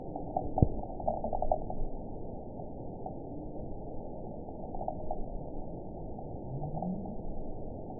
event 917888 date 04/19/23 time 21:01:33 GMT (2 years, 7 months ago) score 9.62 location TSS-AB03 detected by nrw target species NRW annotations +NRW Spectrogram: Frequency (kHz) vs. Time (s) audio not available .wav